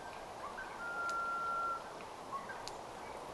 punatulkun hieno pieni sävellys
punatulkun_hieno_pieni_savellys.mp3